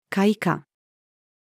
階下-female.mp3